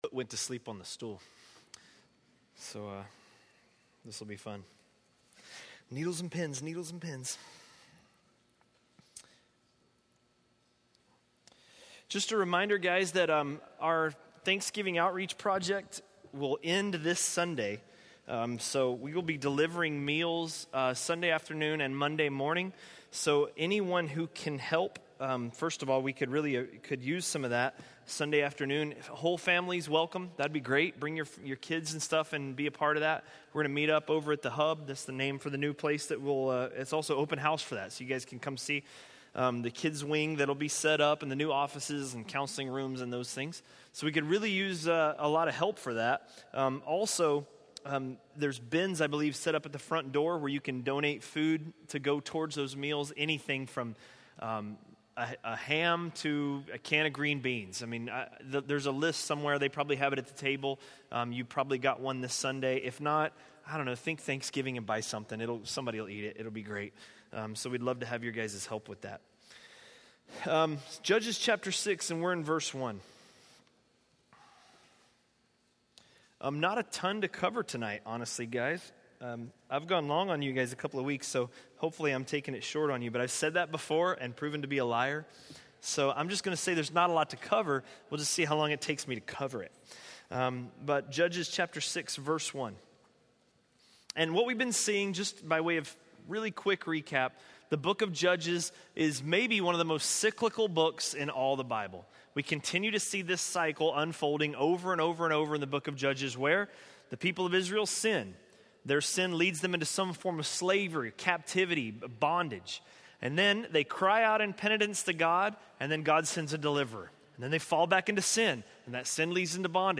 A message from the series "(Untitled Series)." by